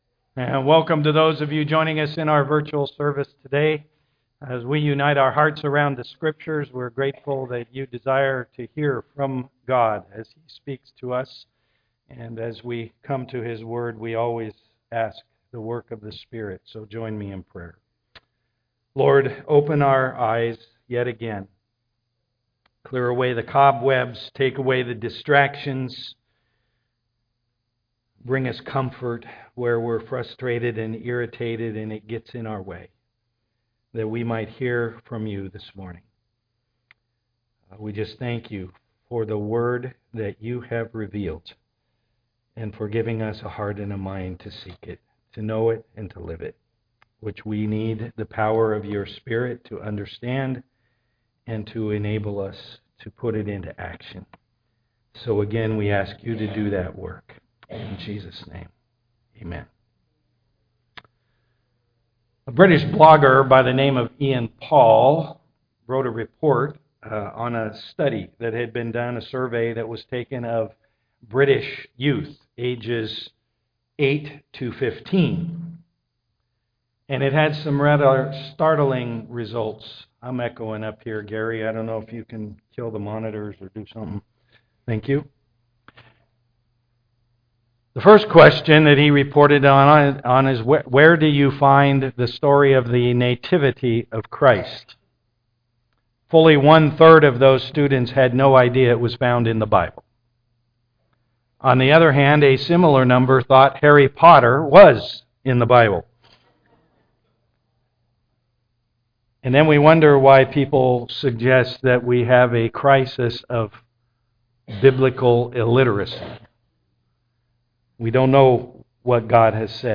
1 Thessalonians 2:13-20 Service Type: am worship Does it really matter if the Bible speaks God's Word?